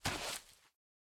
Minecraft Version Minecraft Version snapshot Latest Release | Latest Snapshot snapshot / assets / minecraft / sounds / mob / turtle / walk4.ogg Compare With Compare With Latest Release | Latest Snapshot
walk4.ogg